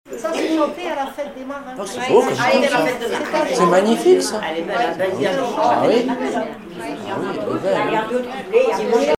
Informateur(s) Club d'anciens de Saint-Pierre association
Catégorie Témoignage